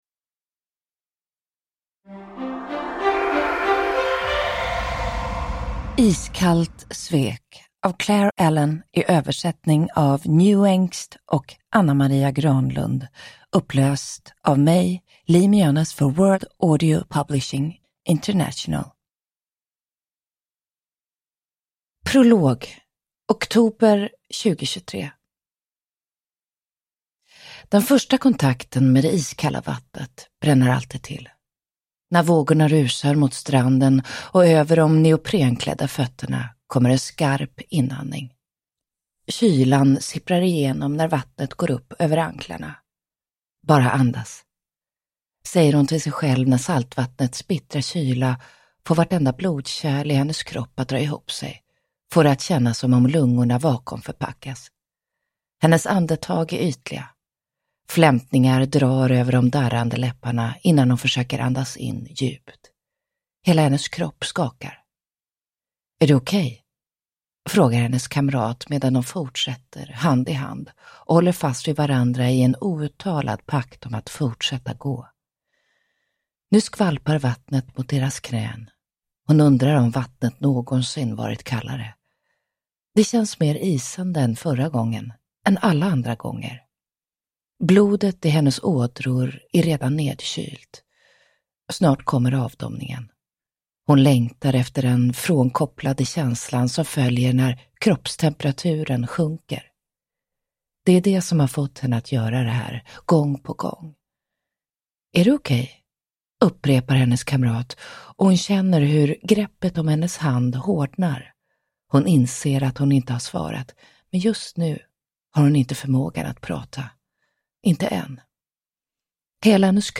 Uppläsare: Liv Mjönes